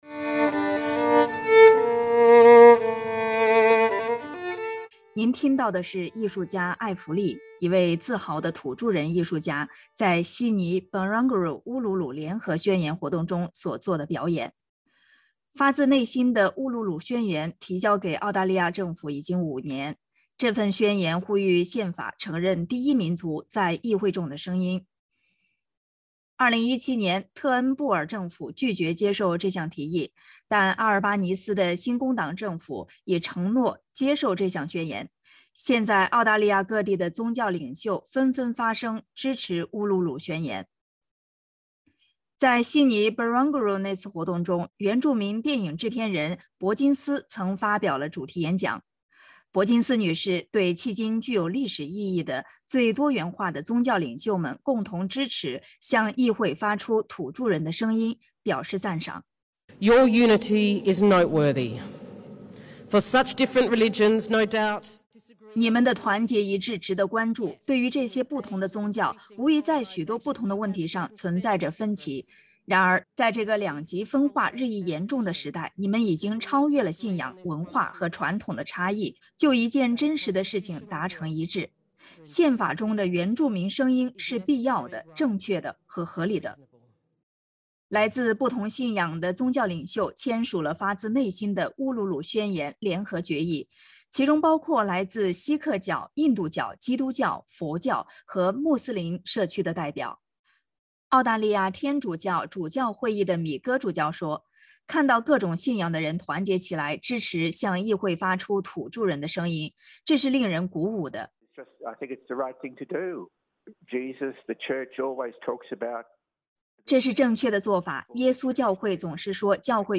Rachel Perkins speaks at the event at Barangaroo in Sydney (SBS) Source: SBS